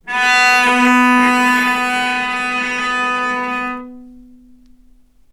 vc_sp-B3-ff.AIF